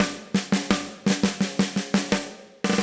Index of /musicradar/80s-heat-samples/85bpm
AM_MiliSnareB_85-01.wav